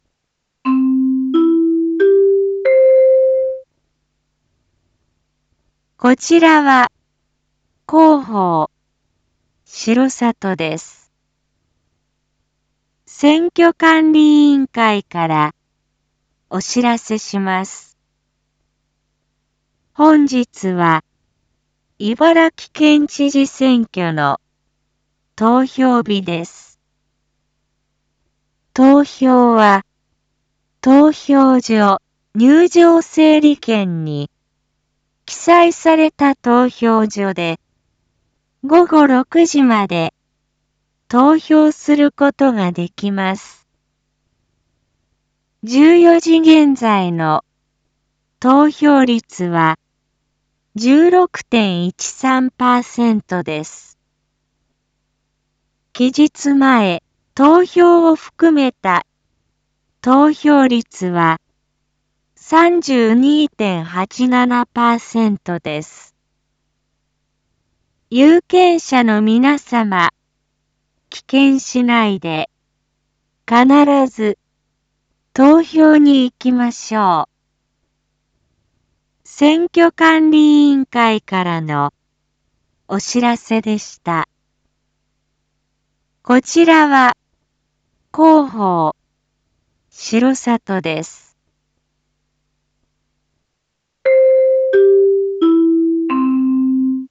Back Home 一般放送情報 音声放送 再生 一般放送情報 登録日時：2021-09-05 14:21:43 タイトル：R3.9.5 14時放送 茨城県知事選挙投票状況 インフォメーション：こちらは、広報しろさとです。